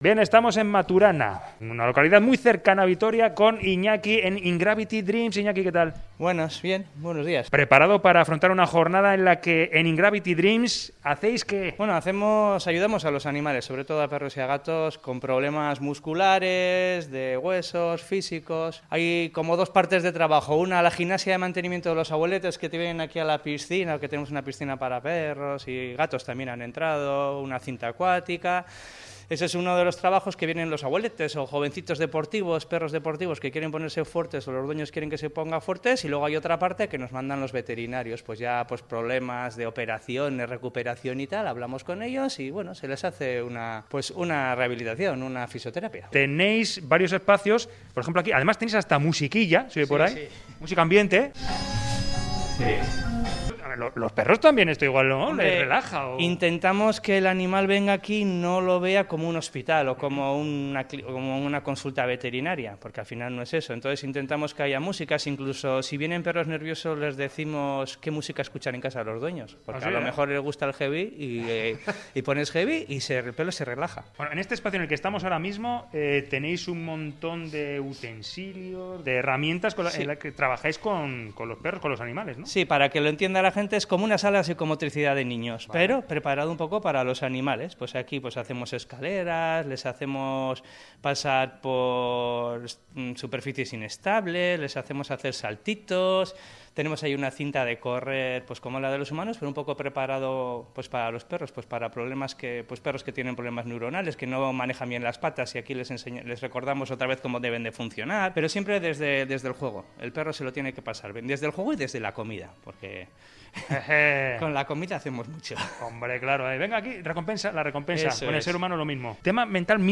Audio: Reportaje: Bienestar animal con masajes y acupuntura